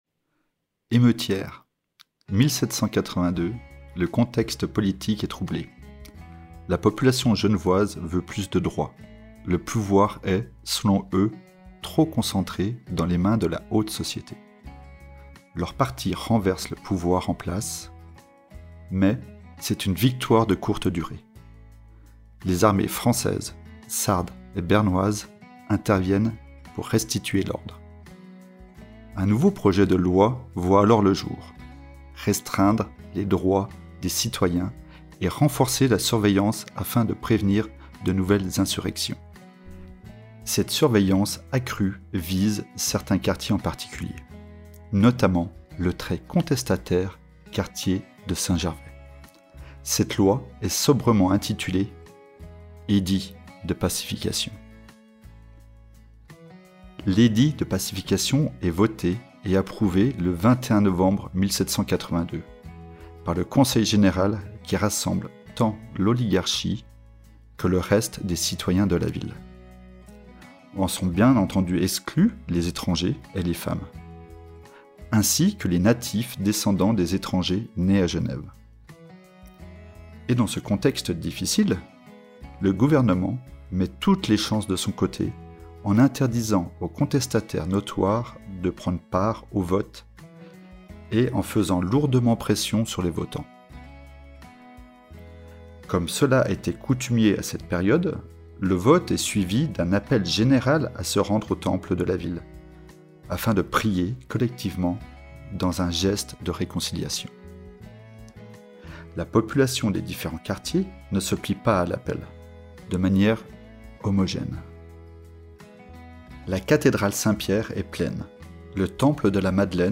Lues par